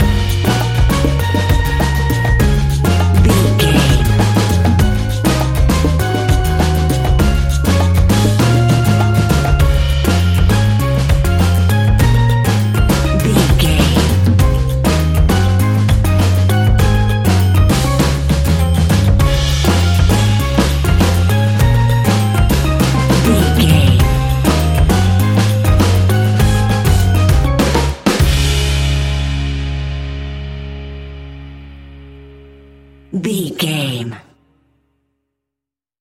Ionian/Major
D♭
calypso
steelpan
happy
drums
percussion
bass
brass
guitar